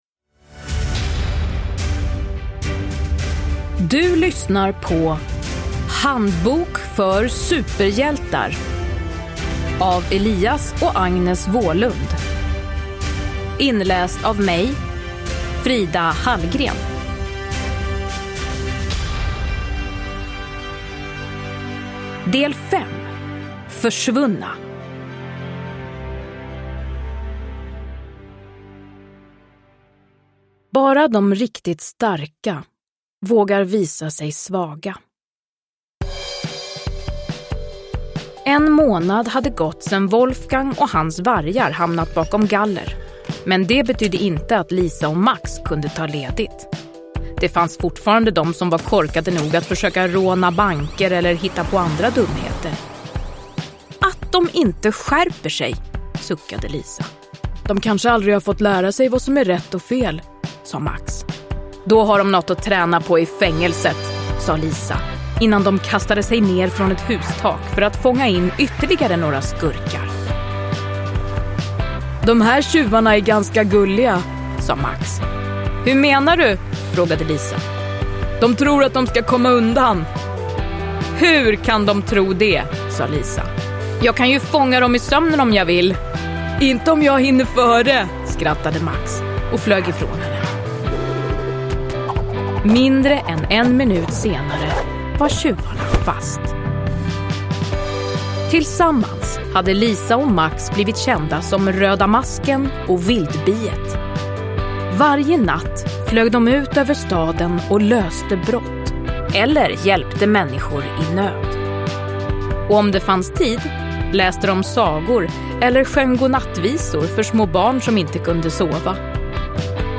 Handbok för superhjältar. Försvunna – Ljudbok – Laddas ner